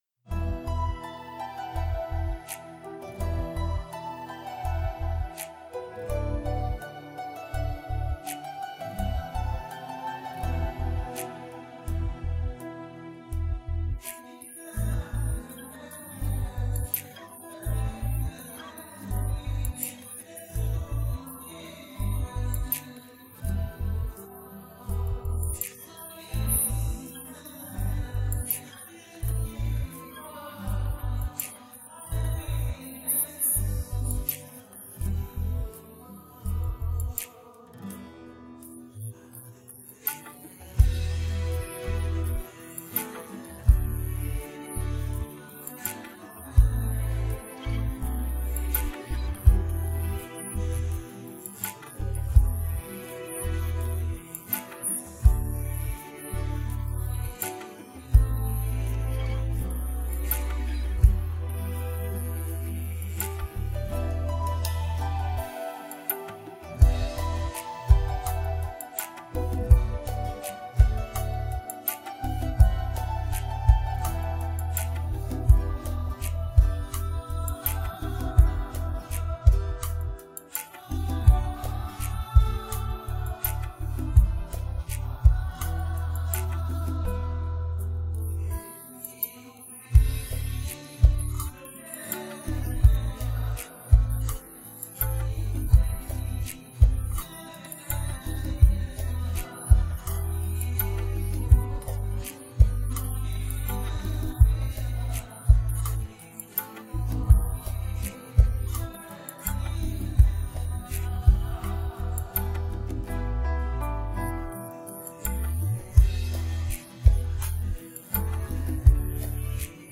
Play karaoke & Sing with Us